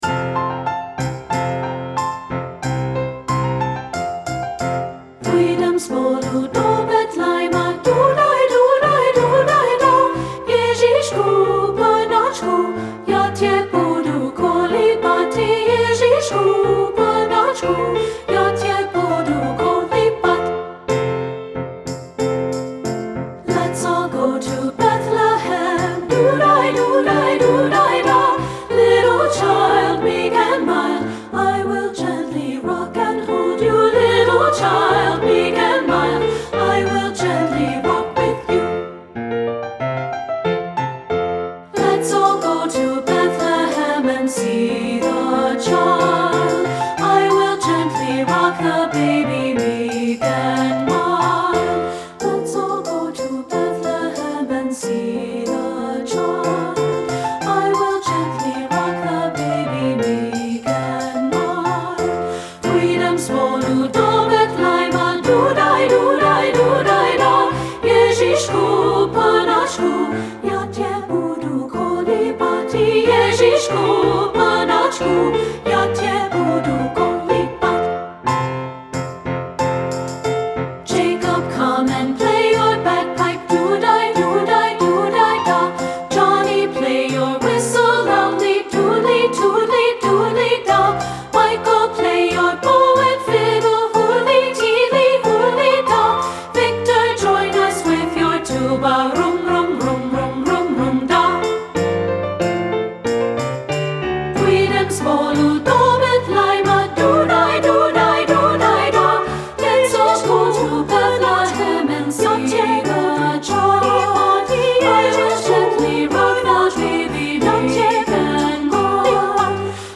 • Soprano 1
• Soprano 2
• Alto
• Tambourine
• Piano
Studio Recording
Ensemble: Treble Chorus
Accompanied: Accompanied Chorus